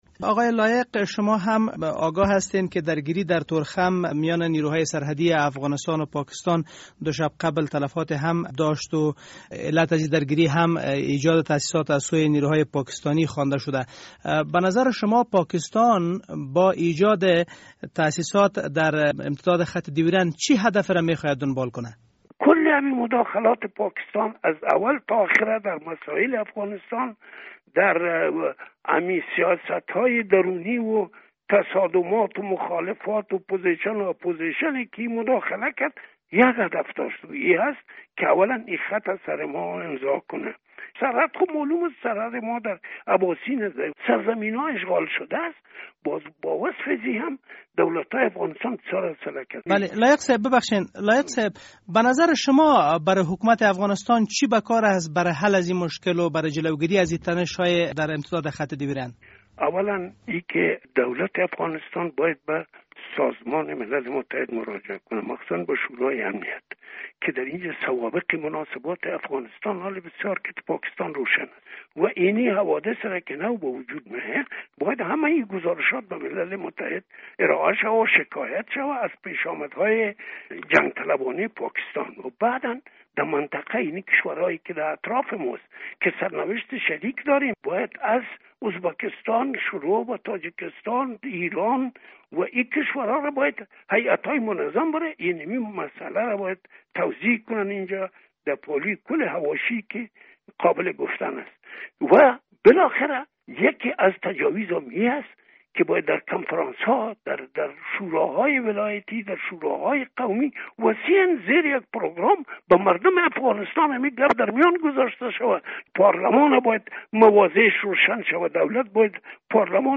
مصاحبه با سلیمان لایق: